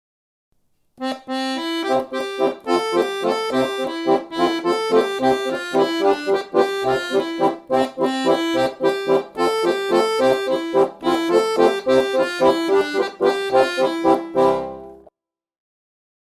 Melodía e acompañamento
melo_y_acomp_parte_1.mp3